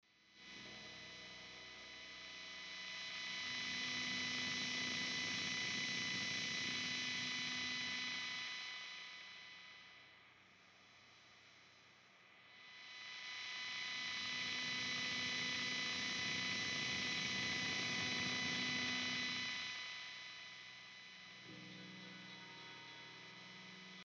Фон при записи электрогитар